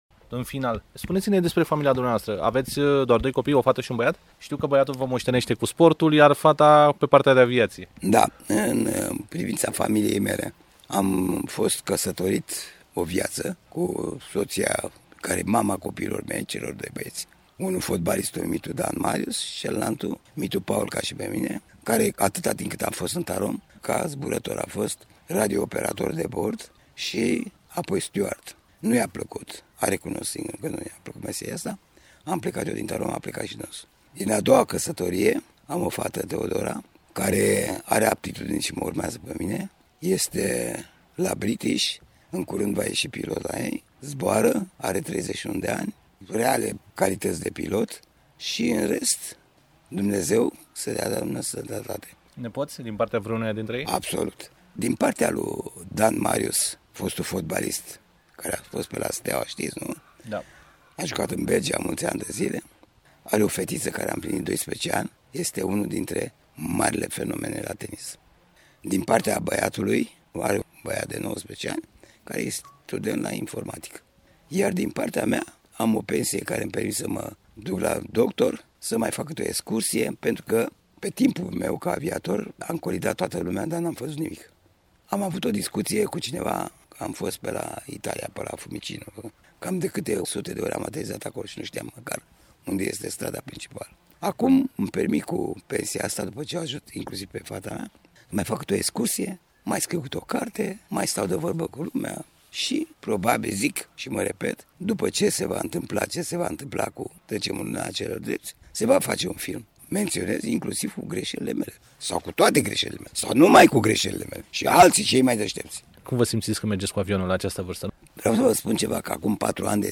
V-am pregătit un interviu cu adevărat inedit, pe care vă invit să îl savurați pe îndelete pentru că veți afla informații și detalii extrem de interesante și unice.